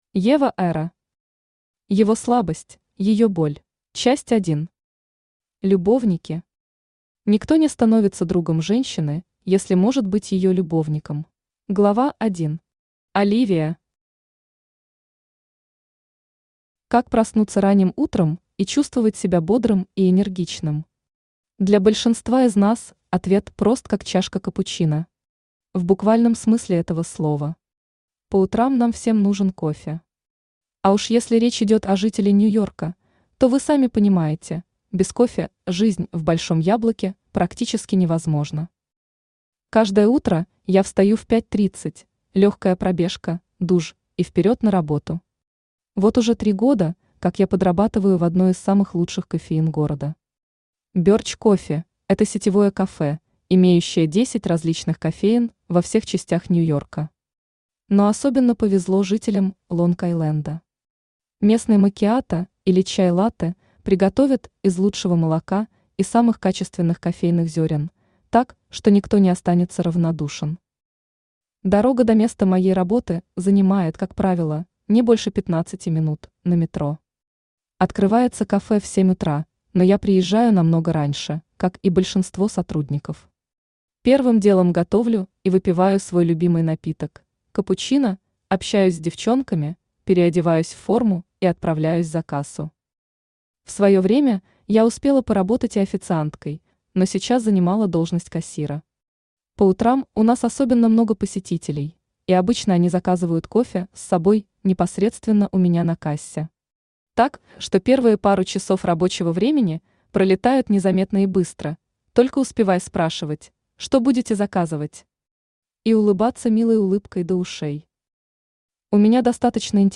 Аудиокнига Его слабость, её боль | Библиотека аудиокниг
Aудиокнига Его слабость, её боль Автор Ева Эра Читает аудиокнигу Авточтец ЛитРес.